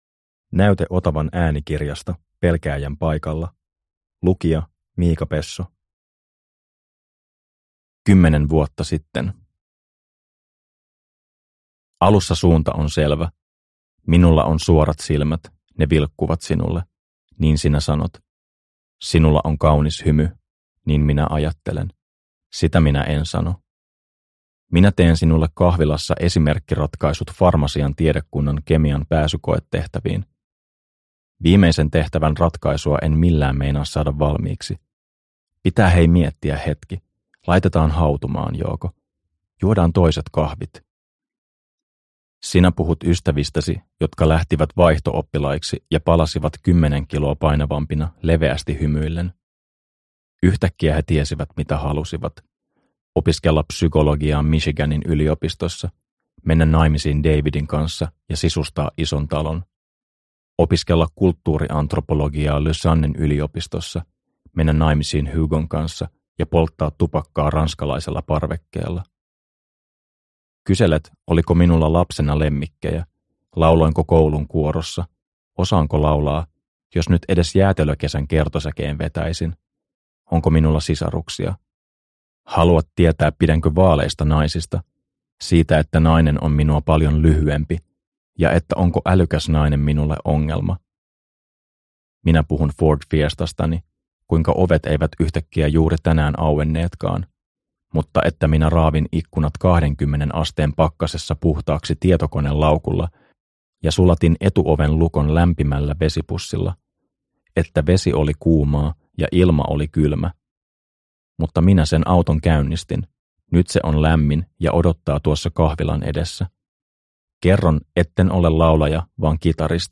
Pelkääjän paikalla – Ljudbok – Laddas ner